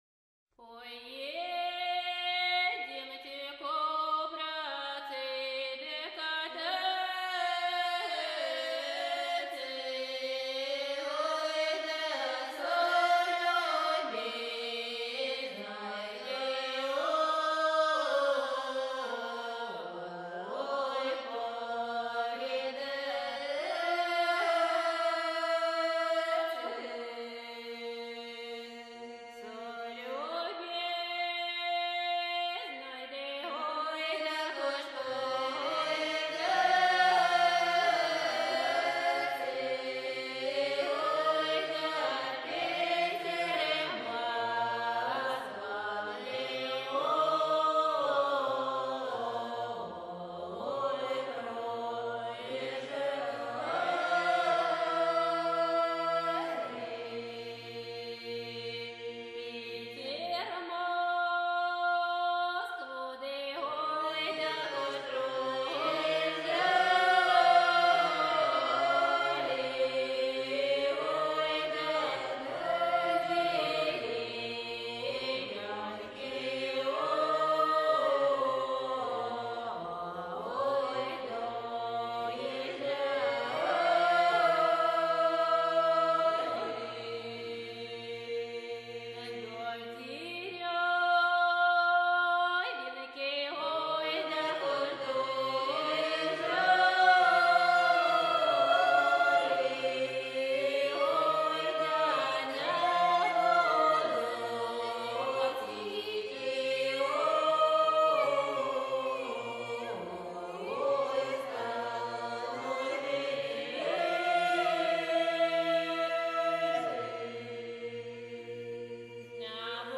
2."На солнечном исходе"(народная) -